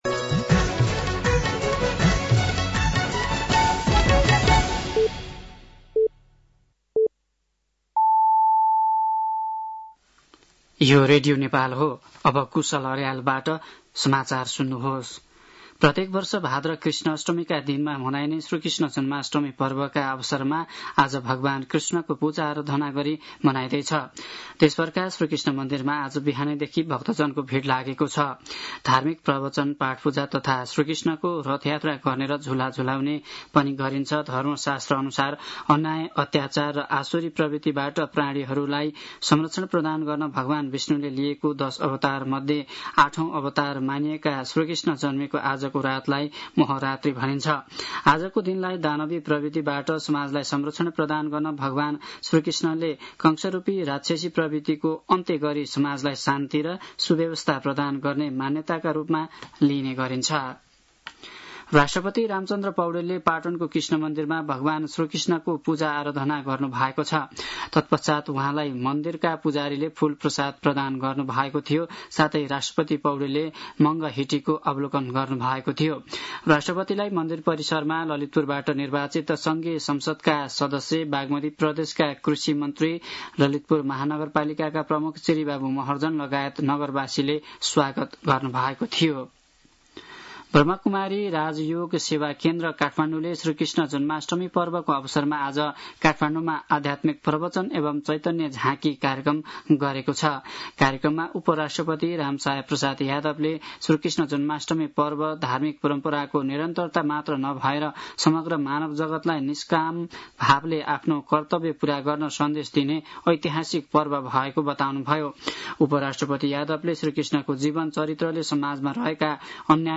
साँझ ५ बजेको नेपाली समाचार : ३१ साउन , २०८२
5.-pm-nepali-news-1-5.mp3